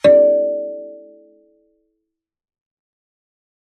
kalimba2_wood-D4-mf.wav